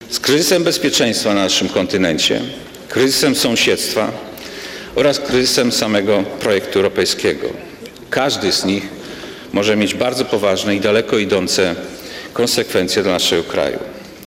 Przed Polską w tym roku są trzy wielkie kryzysy, z którymi musimy się zmierzyć – mówił w Sejmie szef naszego MSZ..
Wystąpienie Witolda Waszczykowskiego już się zakończyło.